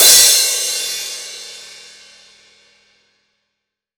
Index of /90_sSampleCDs/AKAI S6000 CD-ROM - Volume 3/Crash_Cymbal1/16-17_INCH_CRASH